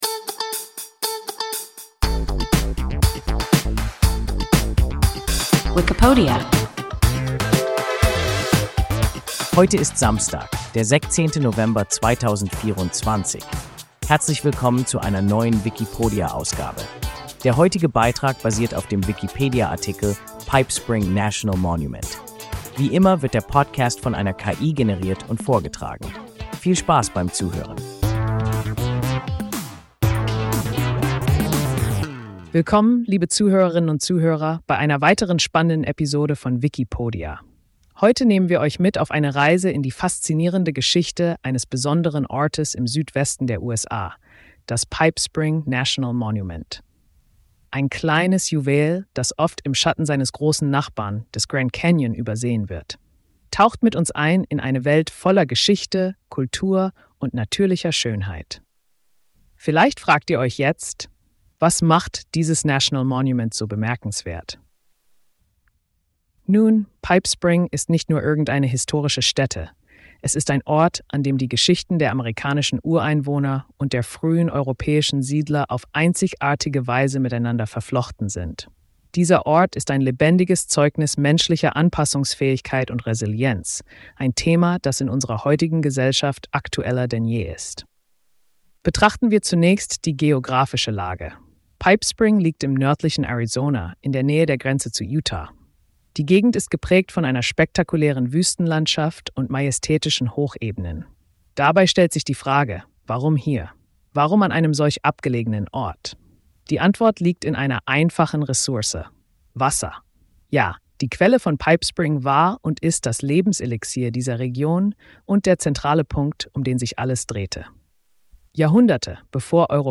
Pipe Spring National Monument – WIKIPODIA – ein KI Podcast